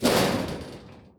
Metal_BigHit.wav